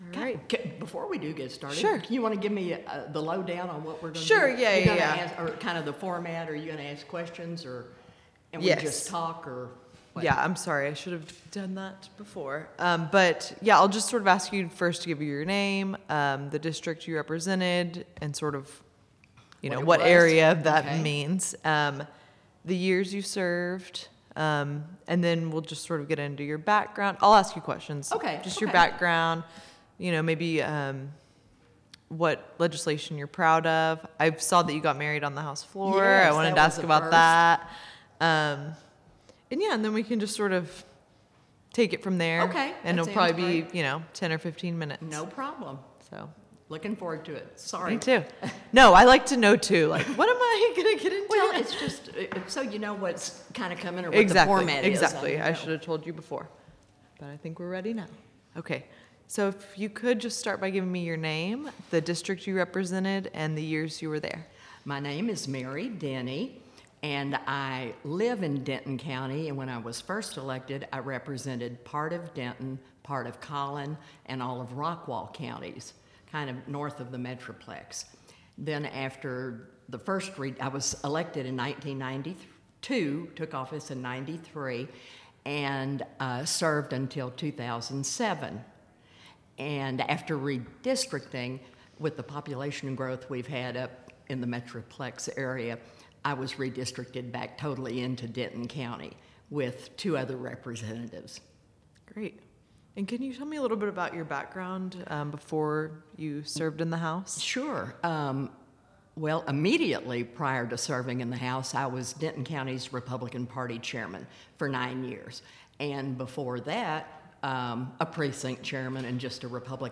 Oral history interview with Mary Denny, 2017.